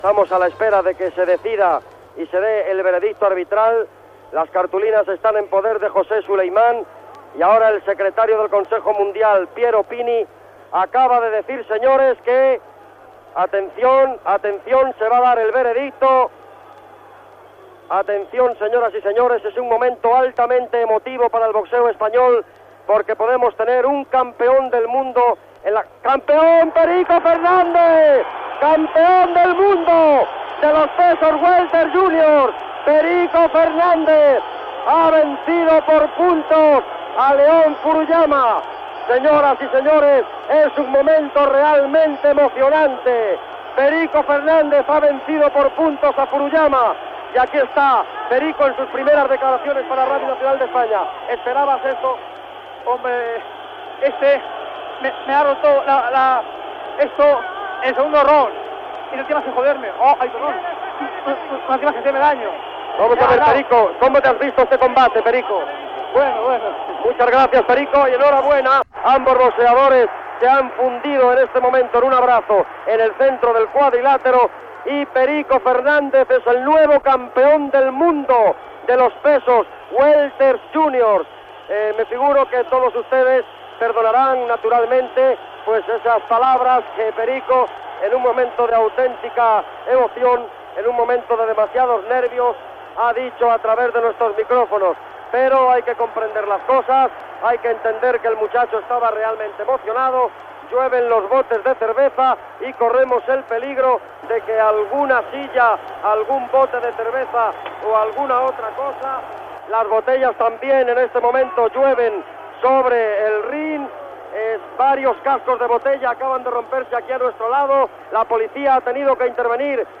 Transmissió, des de Roma, del resultat del combat de boxa entre Pedro "Perico" Fernández i Tetsu "Lion" Furuyama que va suposar la victòria del primer, per punts, i ser proclamat campió del món dels premis Welter Junior
Esportiu